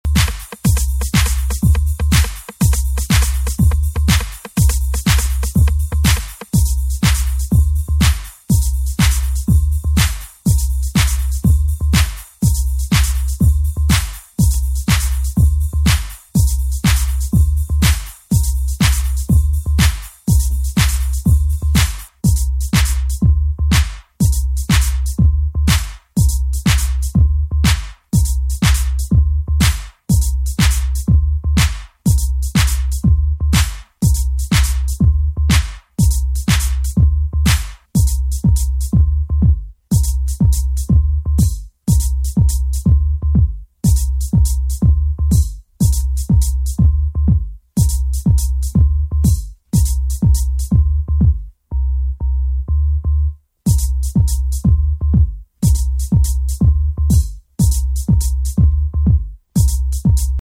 ホーム > HOUSE/BROKEN BEAT > V.A